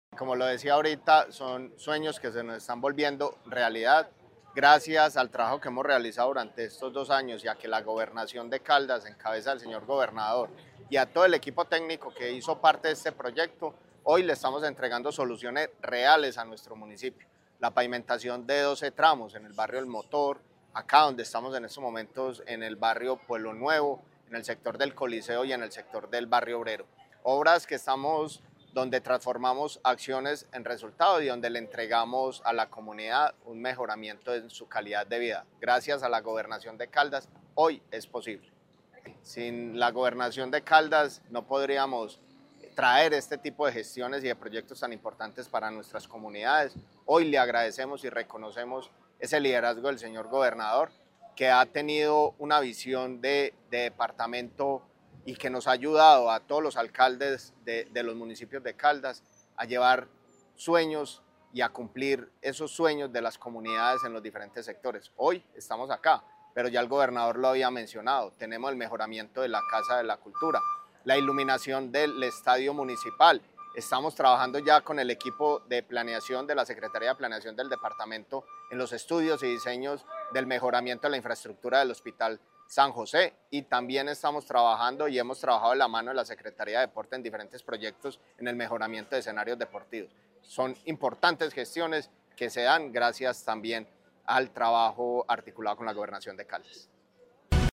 Néstor Javier Ospina Grajales, alcalde de Viterbo.